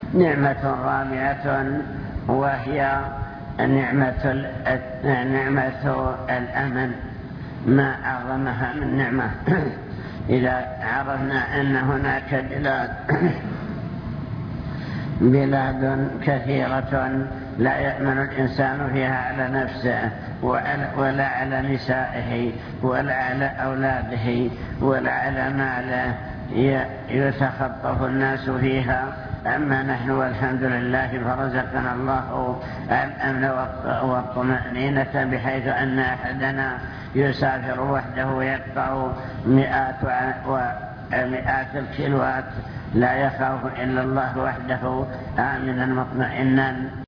المكتبة الصوتية  تسجيلات - محاضرات ودروس  محاضرة بعنوان شكر النعم (2) نعم الله تعالى وعظمها